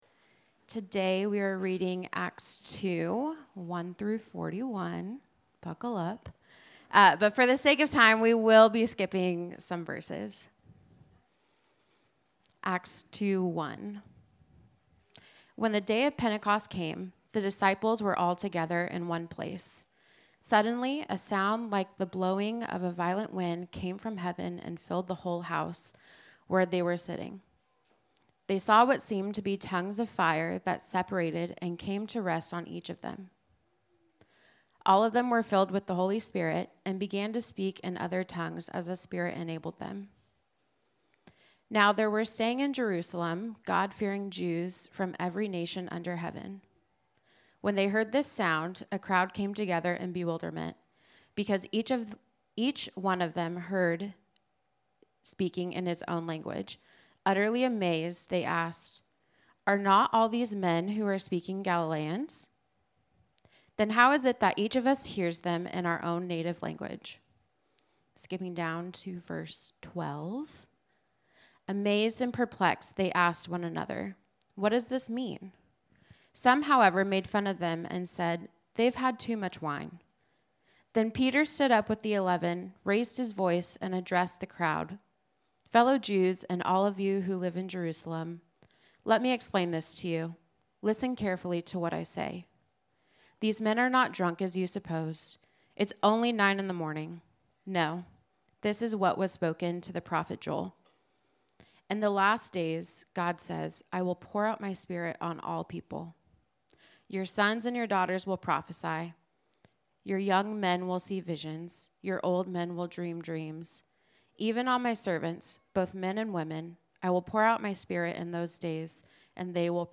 Sermon-1.mp3